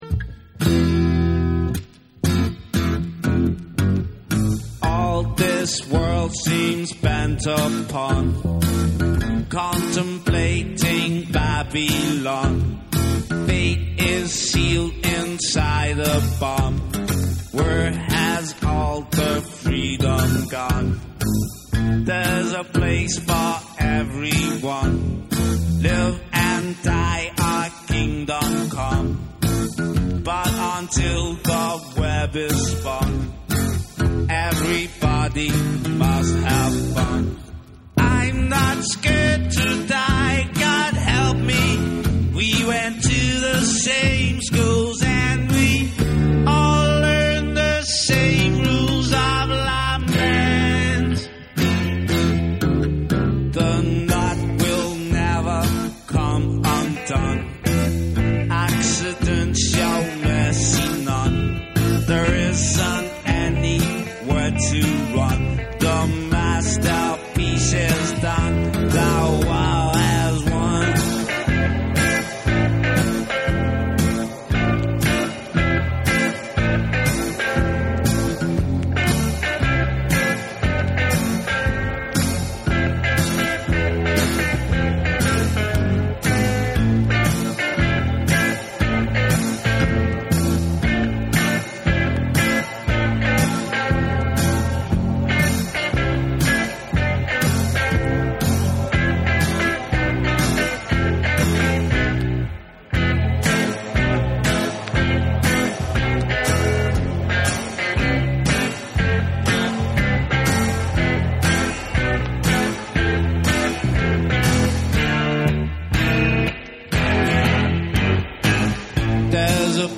切ないメロディーとギターサウンドが胸に響く、何時の時代でも色褪せる事のない大名曲です！※チリノイズ入る箇所あり。
NEW WAVE & ROCK